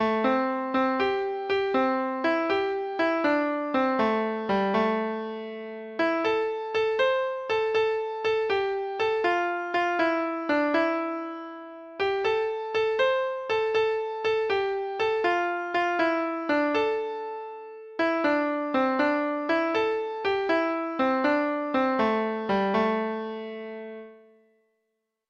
Folk Songs from 'Digital Tradition' Letter J Jim Jones
Free Sheet music for Treble Clef Instrument